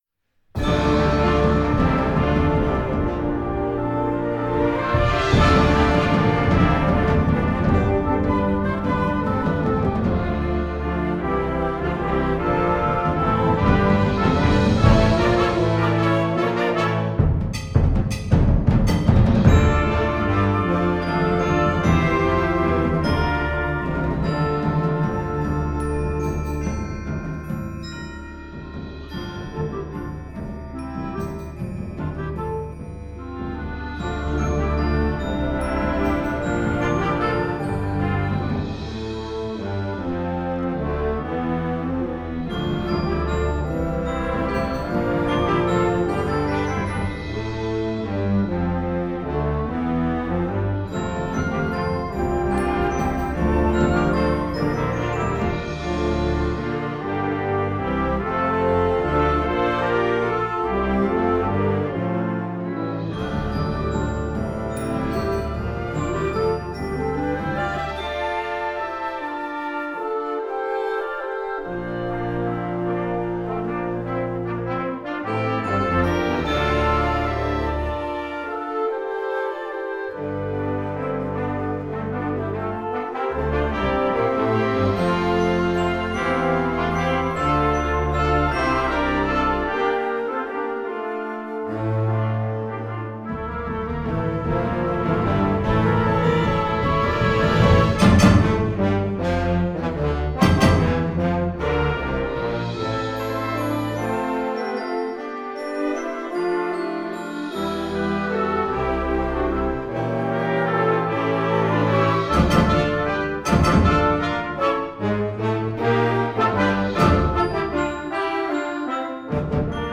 Gattung: Weihnachtslied
Besetzung: Blasorchester